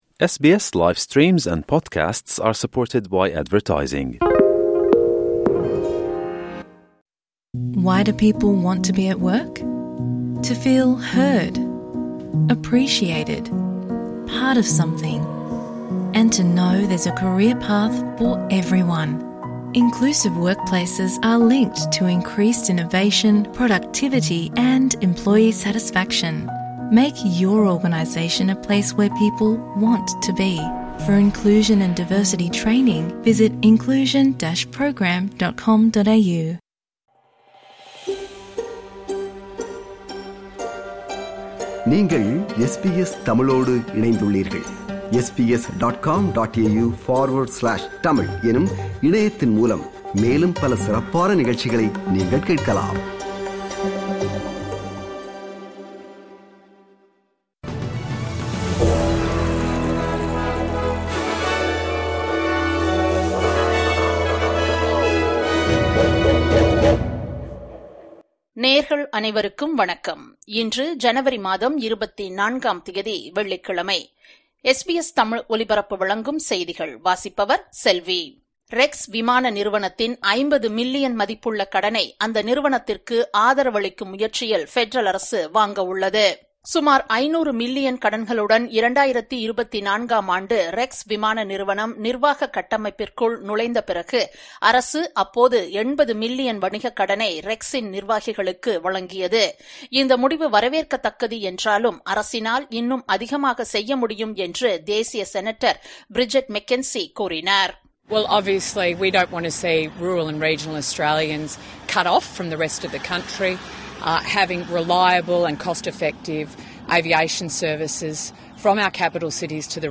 SBS தமிழ் ஒலிபரப்பின் இன்றைய ( வெள்ளிக்கிழமை 24/01/2025) செய்திகள்.